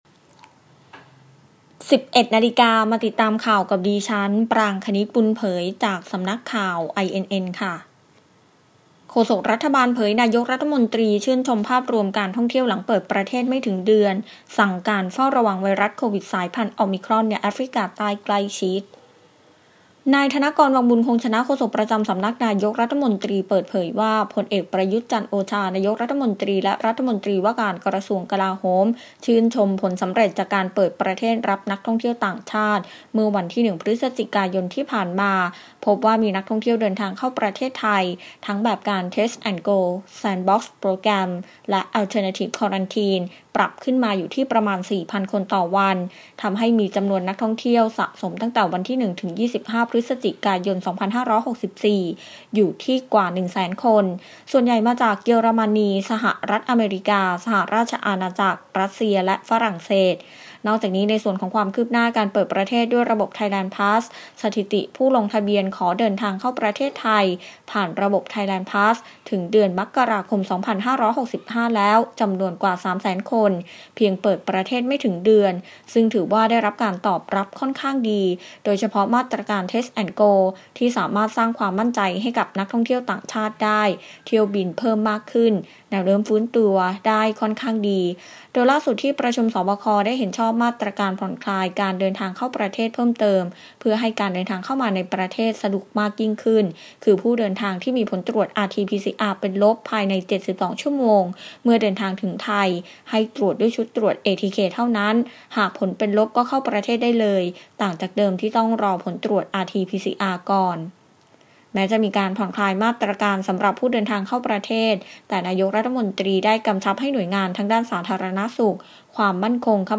ข่าวต้นชั่วโมง 11.00 น.